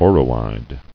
[o·ro·ide]